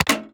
AudioClip_Click-Springy.wav